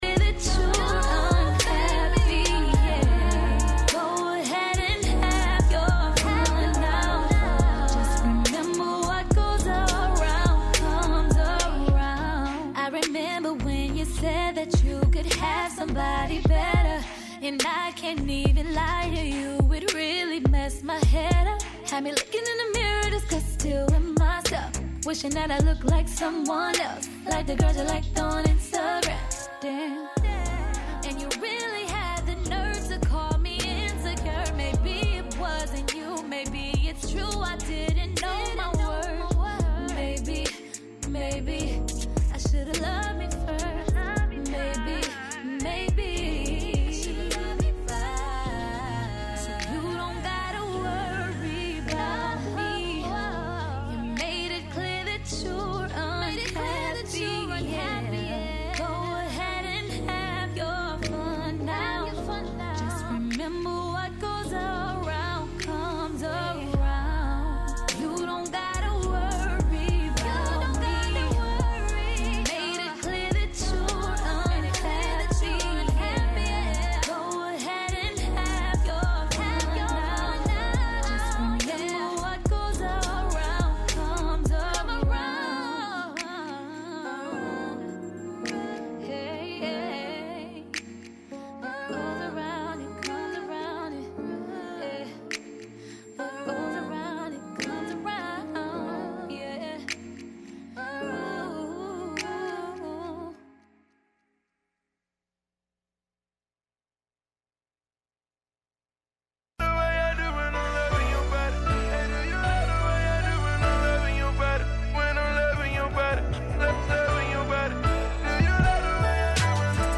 On this show, you’ll hear the recent news, personal experiences and a diverse selection of music.